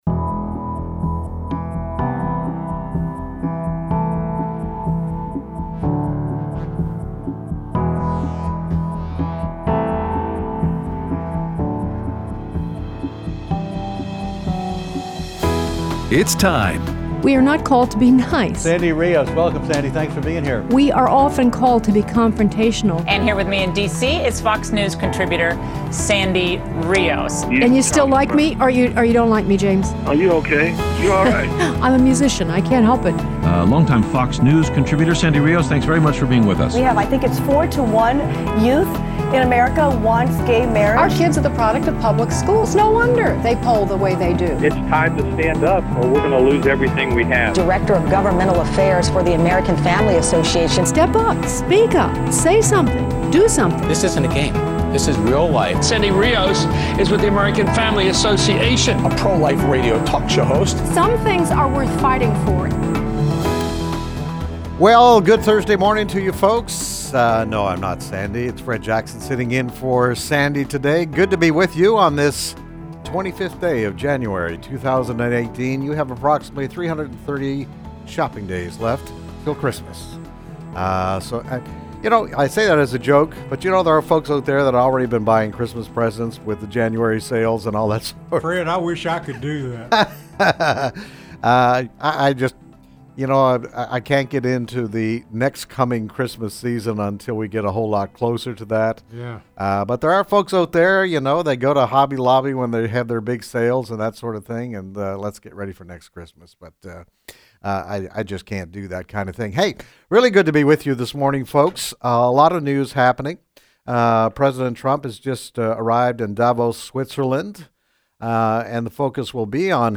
Aired Thursday 1/25/18 on AFR 7:05AM - 8:00AM CST